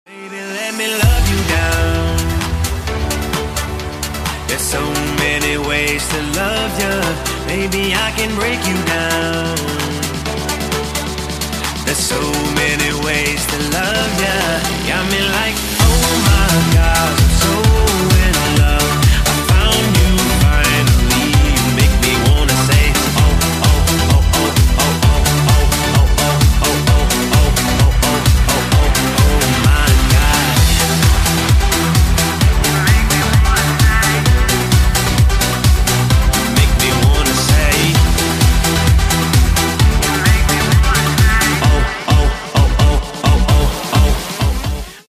• Качество: 128, Stereo
мужской вокал
громкие
dance
Electronic
EDM
электронная музыка